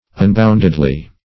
-- Un*bound"ed*ly , adv.
unboundedly.mp3